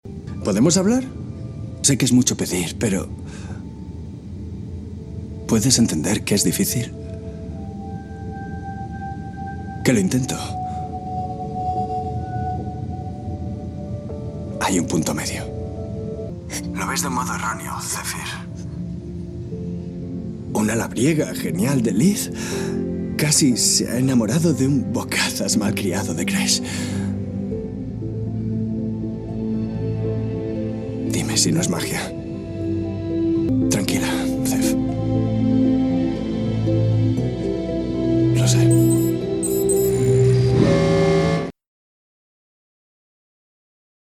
Spanish native actor (also Catalan) with professional experience in international studios with clients like Netflix, HBO, Cartoon Network, AXN, Calle 13, Films, Realitys With home-studio but acces to a professional one
kastilisch
Sprechprobe: Sonstiges (Muttersprache):